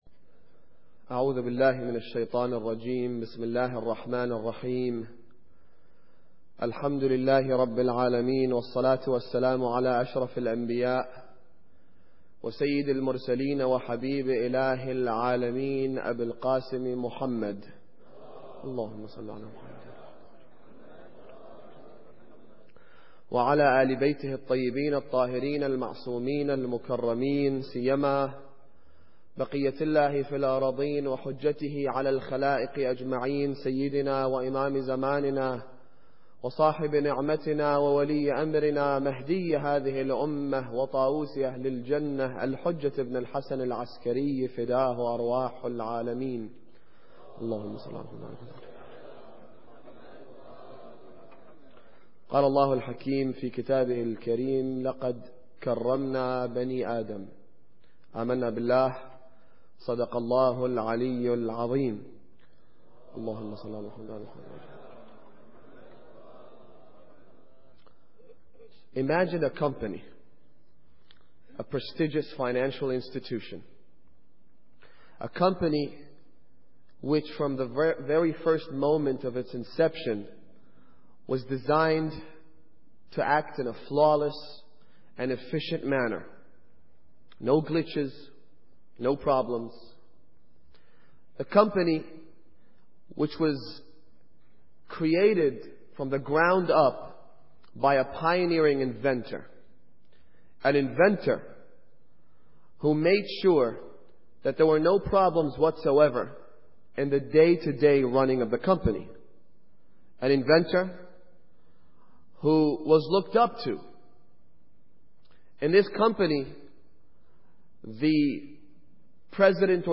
Muharram Lecture 7